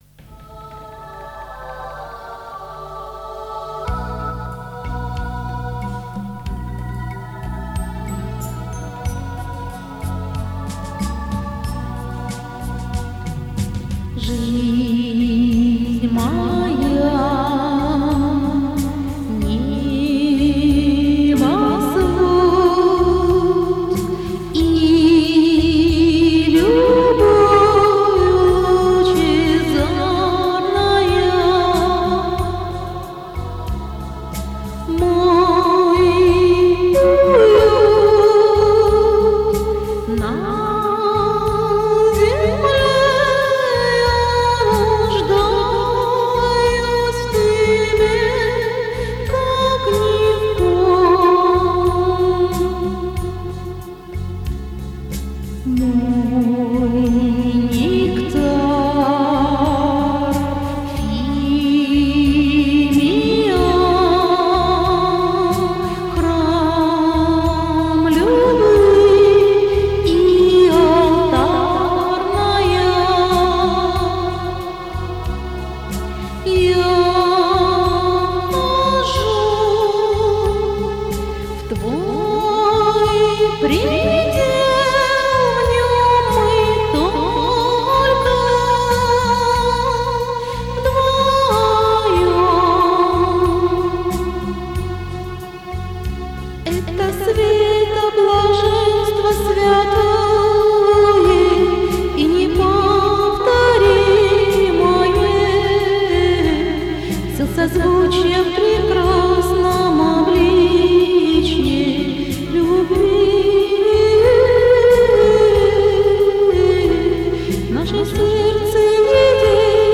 Мистическая музыка Духовная музыка